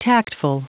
Prononciation du mot tactful en anglais (fichier audio)
Prononciation du mot : tactful